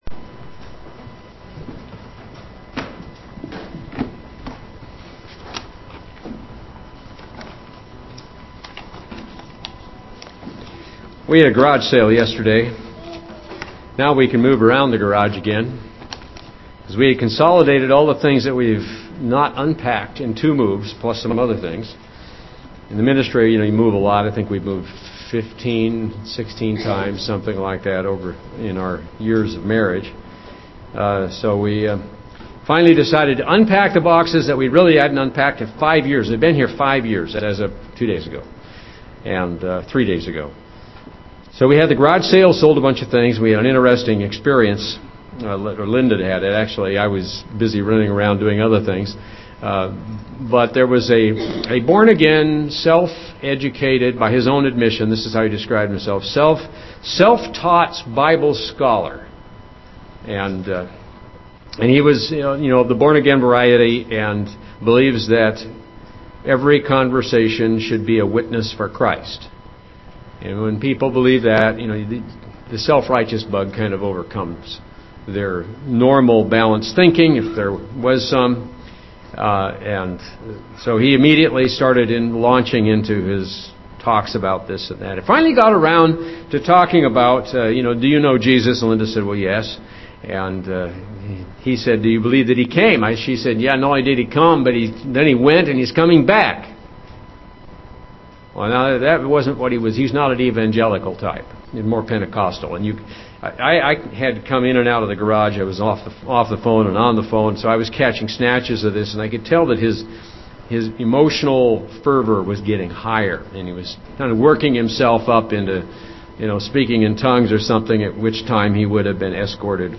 Jesus as a Child UCG Sermon Studying the bible?